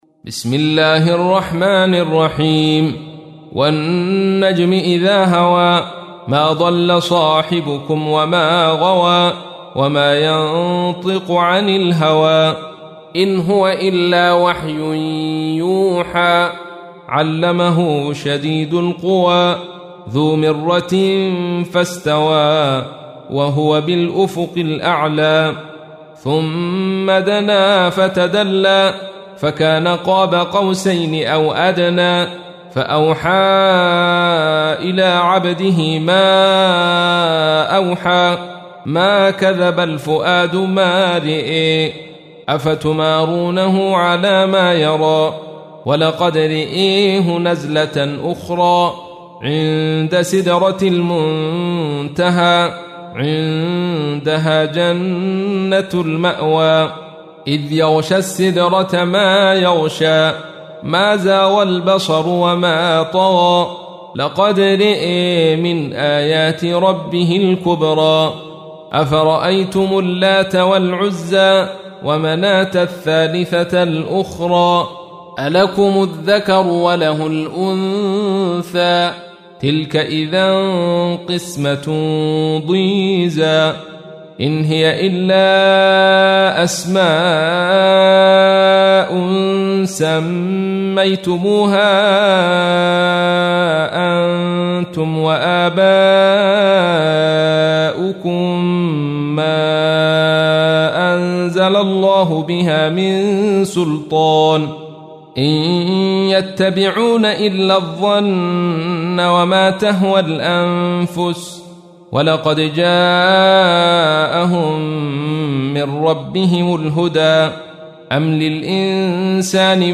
تحميل : 53. سورة النجم / القارئ عبد الرشيد صوفي / القرآن الكريم / موقع يا حسين